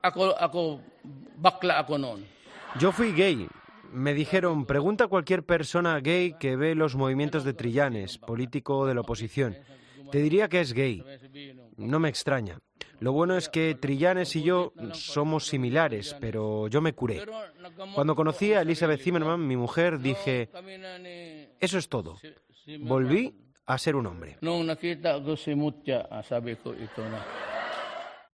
Esta semana está de visita oficial en Tokio. Y allí se reunió con la comunidad filipina en ese país.